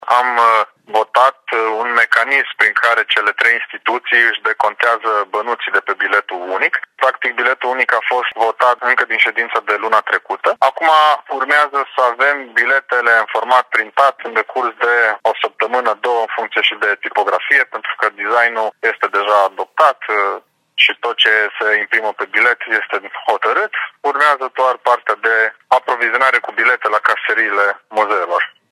Vicepreședintele Alexandru Proteasa spune că CJ Timiș a votat un mecanism prin care cele trei instituții își decontează banii de pe biletul unic.